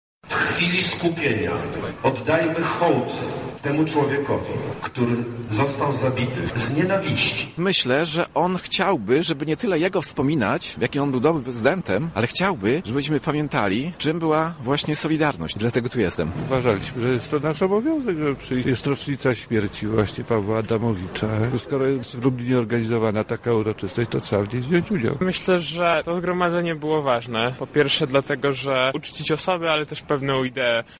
Zapytaliśmy zgromadzonych pod ratuszem mieszkańców, dlaczego chcieli w ten sposób oddać cześć zmarłemu: